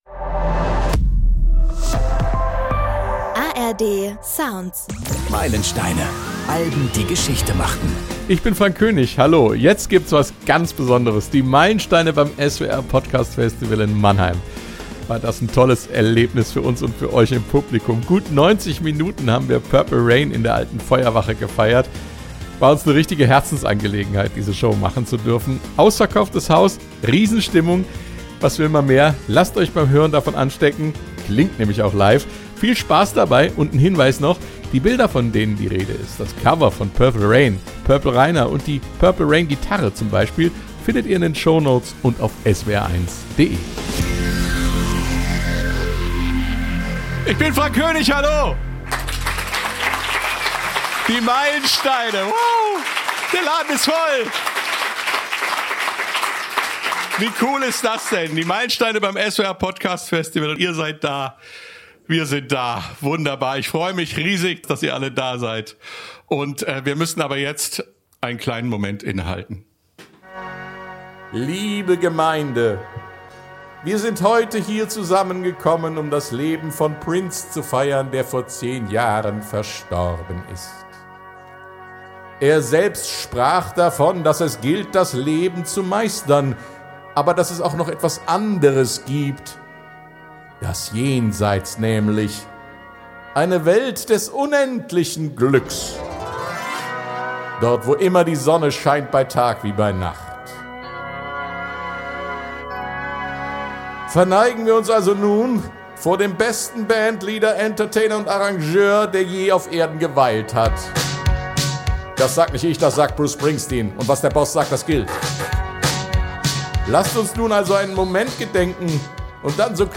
Auf dem SWR Podcastfestival 2026 haben wir ihn und seinem legendären Album "Purple Rain" gefeiert – mit spannenden Gästen.